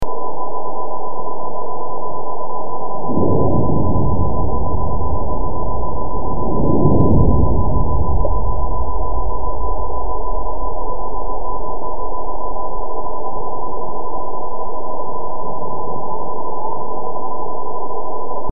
Below there is the event recorded by a vertical geophone at the most remote monitoring station, located in the north-west Italy near the french border.
The seismic signal recorded by the geophone has been accelerated 29 times to make it audible. Two signal waves can be distinguished, the first determined by the body waves, the second by the shear waves, arriving at the Cumiana monitoring station, almost two minutes after the earthquake.
geophone.MP3